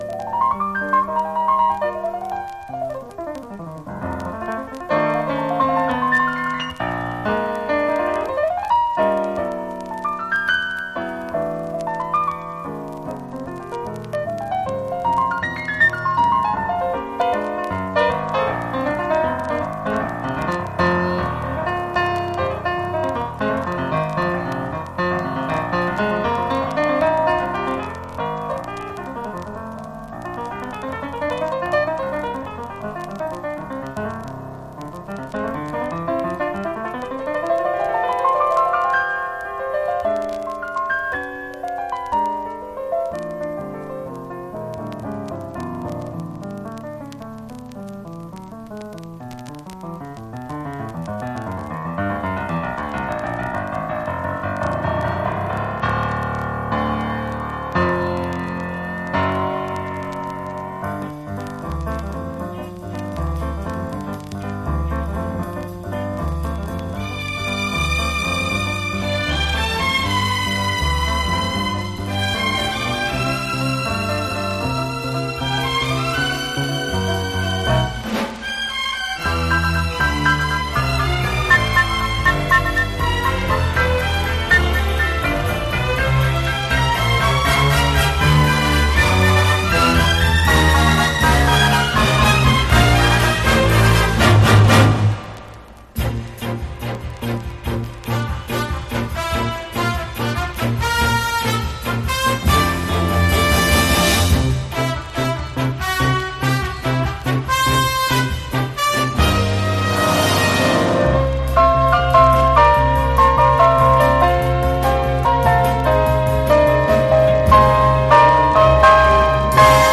華やかな美麗オーケストラル・ピアノ・ラウンジ大傑作！
ジャズ、クラシック、ロック、カントリー、ブルースなどを横断し